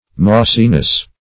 Mossiness \Moss"i*ness\, n. The state of being mossy.